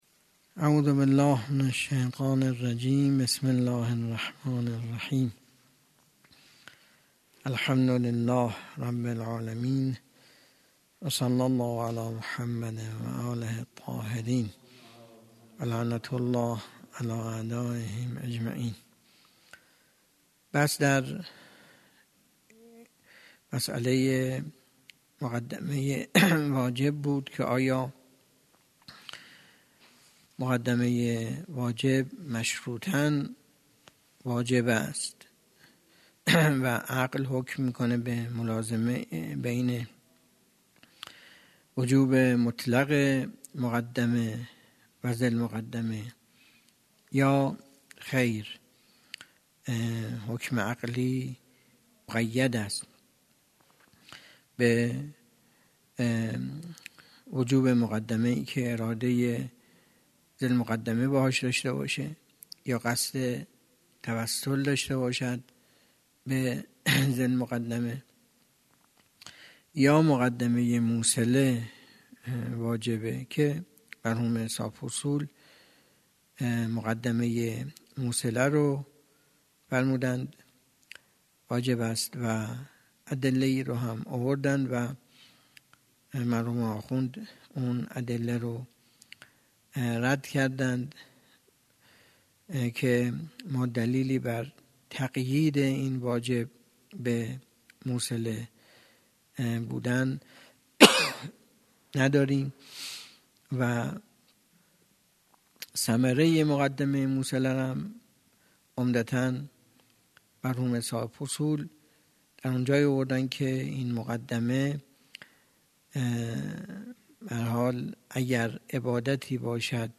درس خارج اصول
سخنرانی